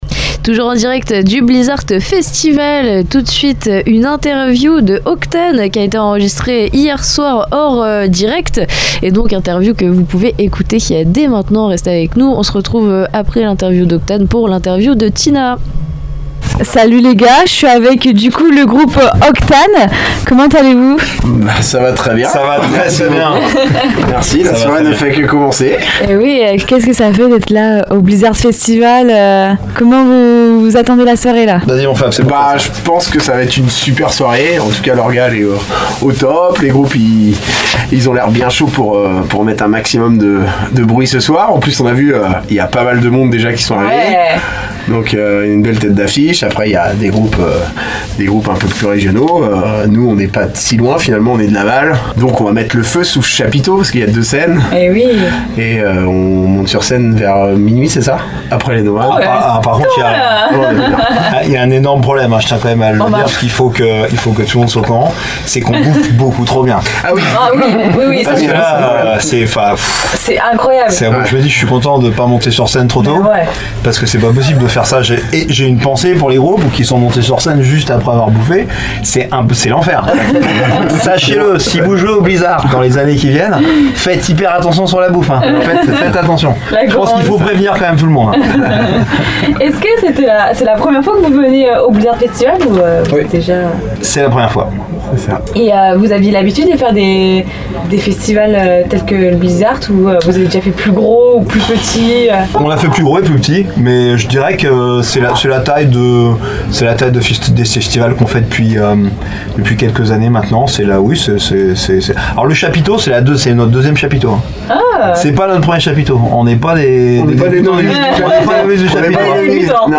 Juste après leur passage sur scène, les membres du groupe ont pris place dans la Mystery Machine (studio radio aménagé dans une camionnette) pour un entretien exclusif diffusé en direct sur Radio Pulse et Radio Coup de Foudre. Dans cette interview, Octane revient sur son identité musicale marquée par le metal alternatif et le hard rock puissant, ainsi que sur son envie de proposer des morceaux à la fois efficaces et personnels. Le groupe partage ses influences, son processus de composition et son rapport viscéral au live, là où leur musique prend toute sa dimension.